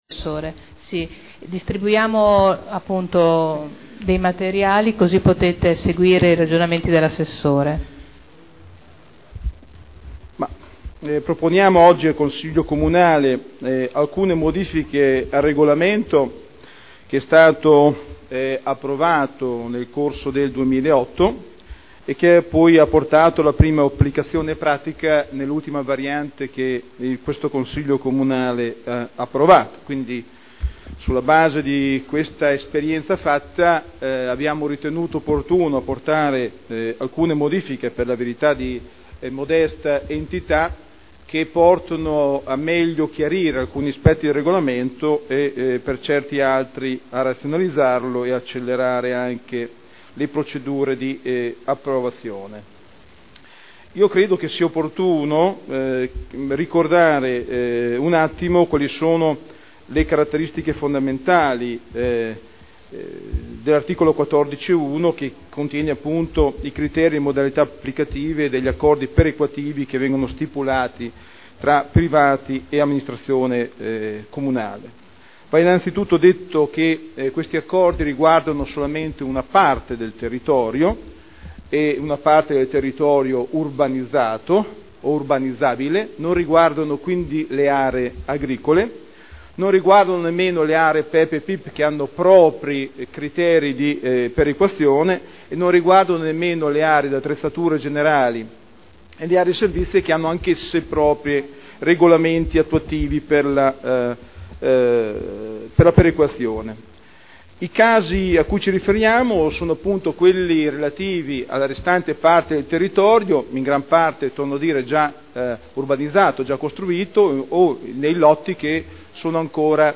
Daniele Sitta — Sito Audio Consiglio Comunale